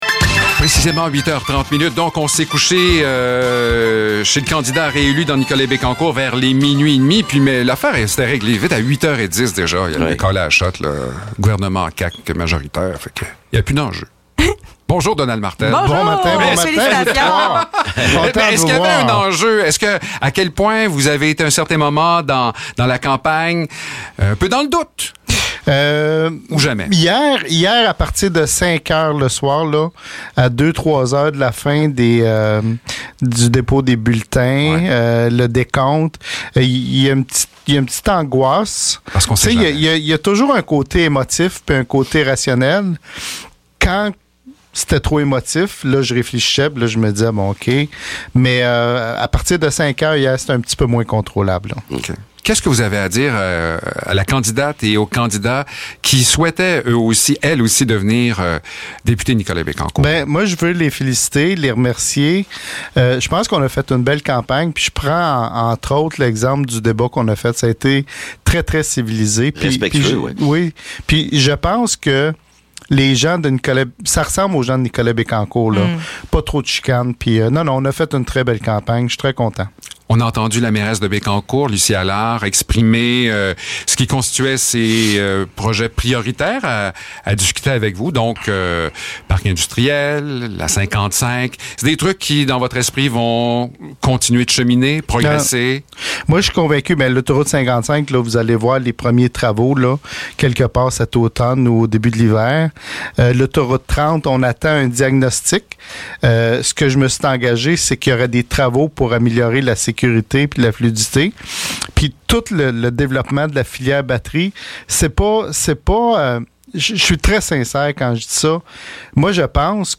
Entrevue avec Donald Martel
Entrevue-Donald-Martel-4-oct-2022.mp3